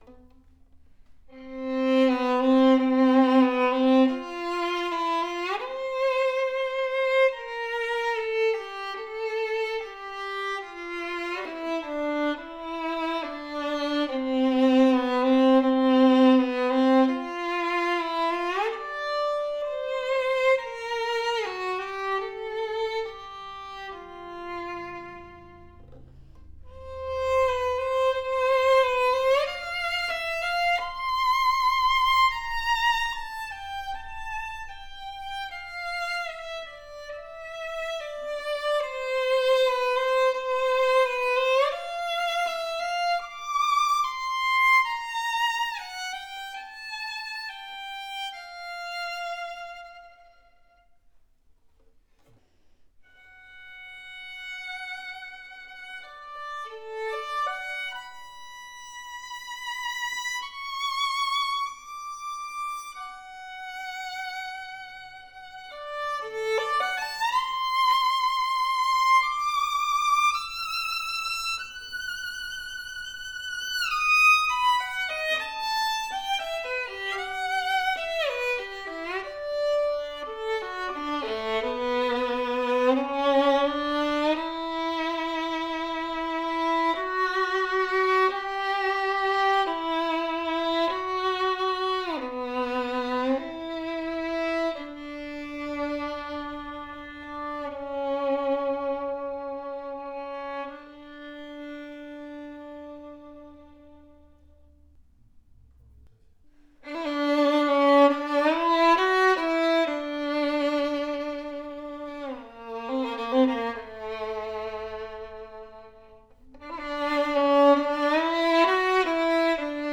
A superior “Messiah” strad copy with resonant and OPEN tone, fast response and sonorous projection.
Ringing and penetrating higher register that projects well and not overly bright, open and pleasant to hear. Full and rounded G string with a deep vibrant voice.